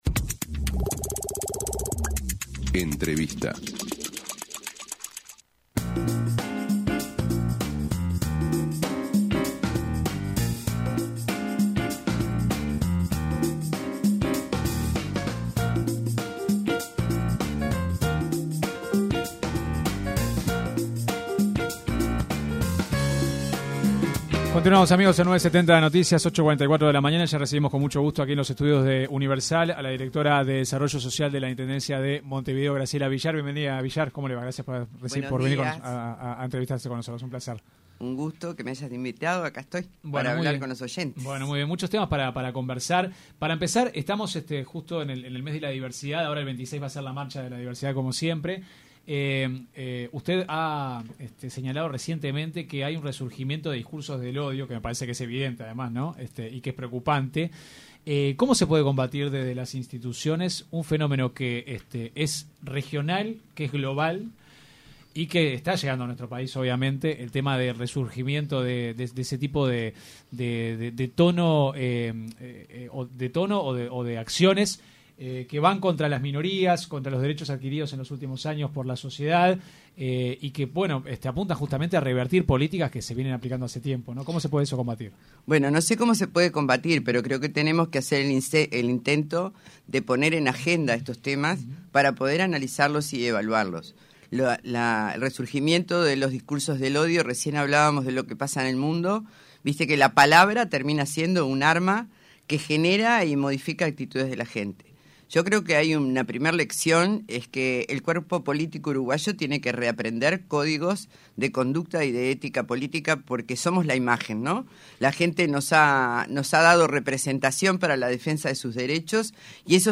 La directora de Desarrollo Social de la Intendencia de Montevideo (IMM), Graciela Villar en entrevista con 970 Noticias, consultada sobre la muerte de los dos niños a manos de su padre en Soriano, dijo que “fallo todo el sistema.